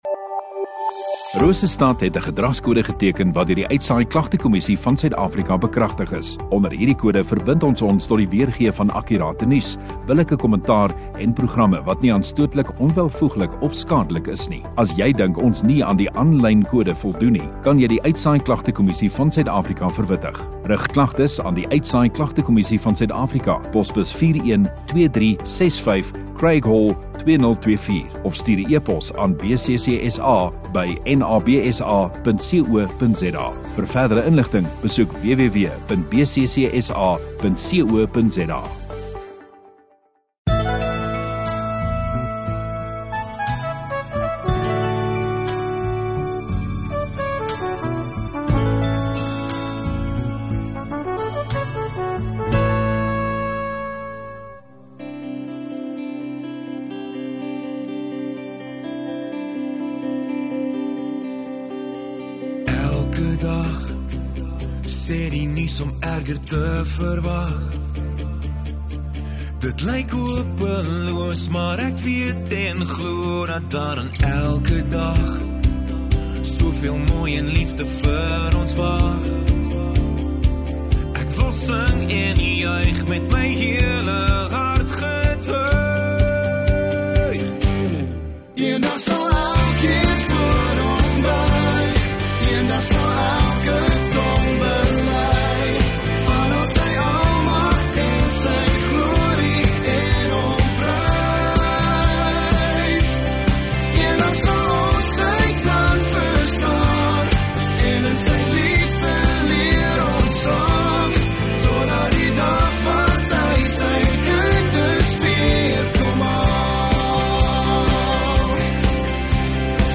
18 May Saterdag Oggenddiens